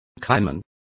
Complete with pronunciation of the translation of caiman.